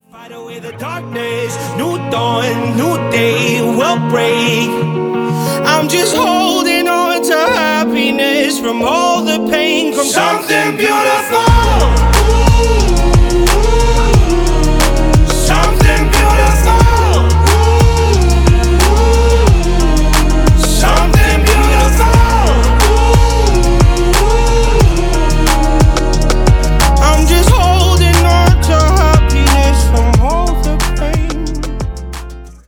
Шансон
громкие